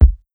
KICK_INSIDE_JOB.wav